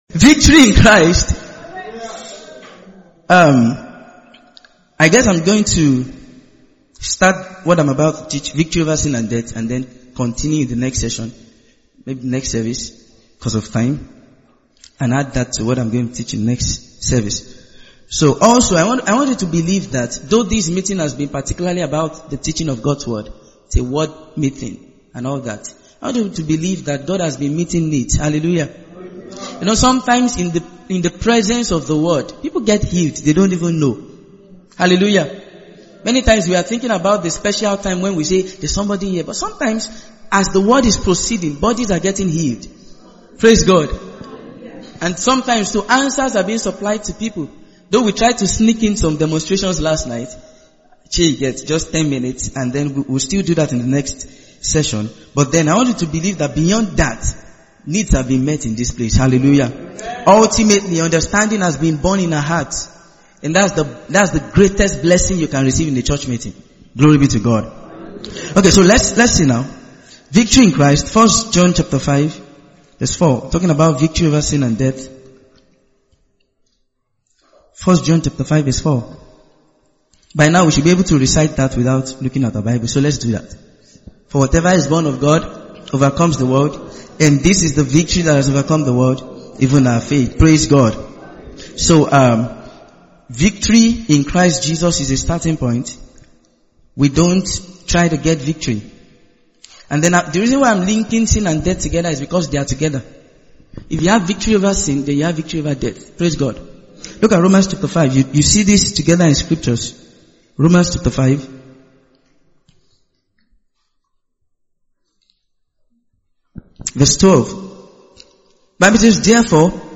Victory in Christ - Part 4 - TSK Church, Lagos